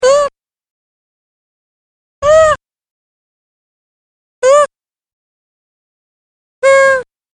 Monkey Voice Pair mp3 sample
monkey-voice-pair-mp3-sample